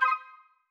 confirm_style_4_002.wav